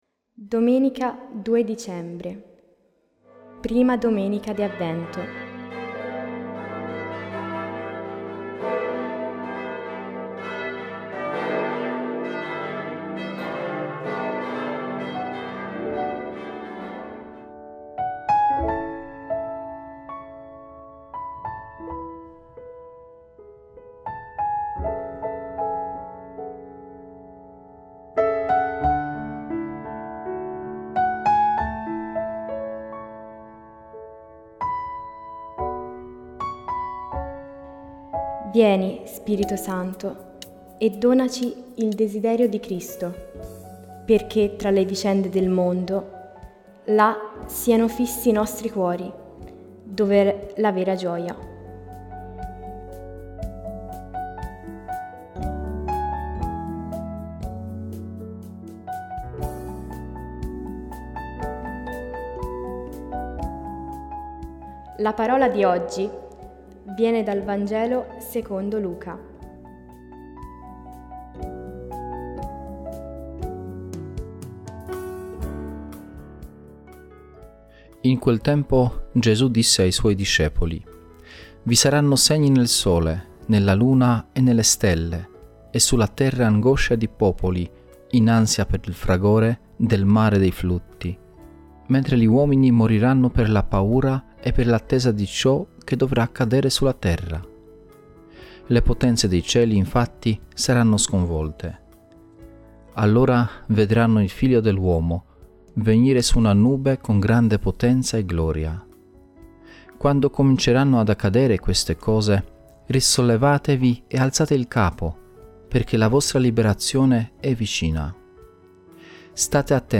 Voci narranti